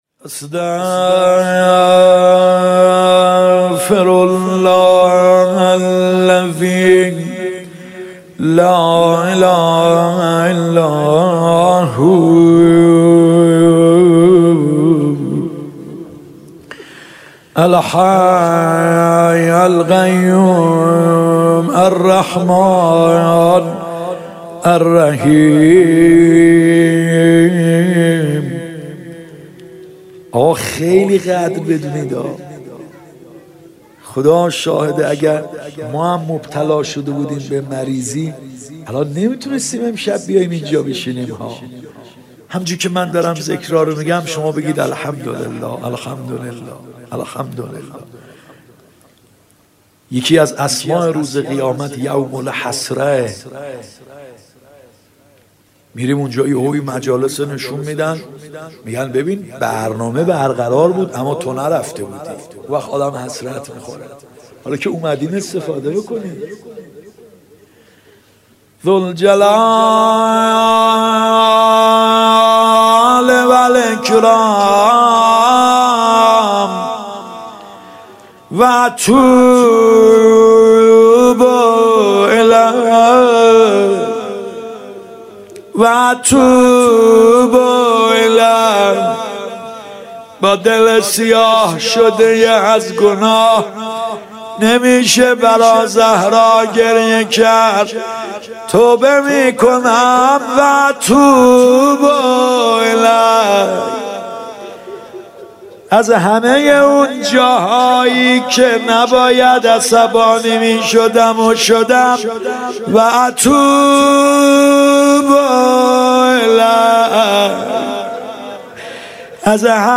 مناجات
فاطمیه اول ( شب دوم) هیات یا مهدی (عج ) 1399